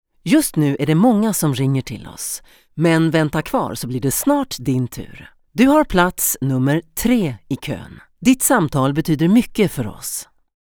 Deep, Accessible, Mature, Warm
Telephony